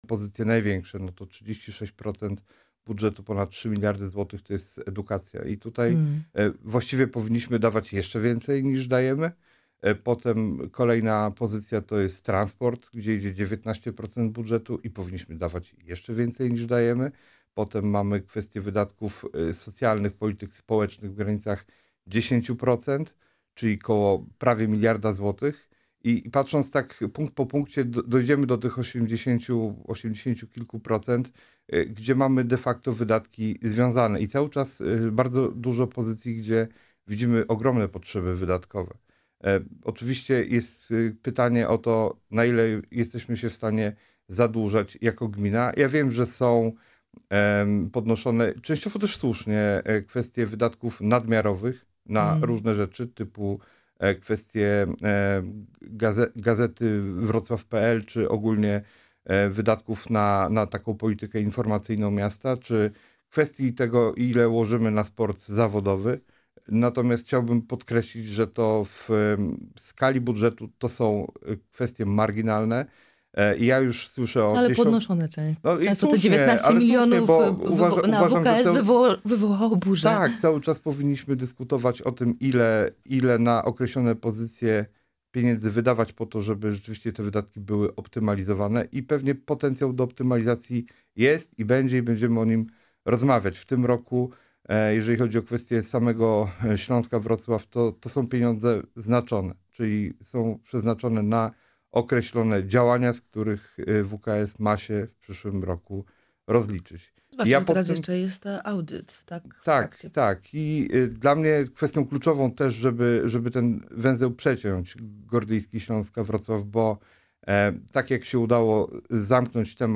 Radni miejscy Wrocławia Klubu Koalicji Obywatelskiej Robert Suligowski oraz Piotr Uhle w audycji „Poranny Gość” rozmawiali na temat budżetu miasta.